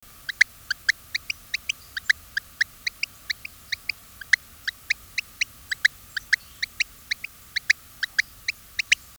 Especie: Eleutherodactylus acmonis
Localidad: Cuba: Yunque de Baracoa, Guantánamo Observaciones: De fondo E. orientalis. Filter high pass 1100 Hz.
Tipo de vocalización: Llamadas de anuncio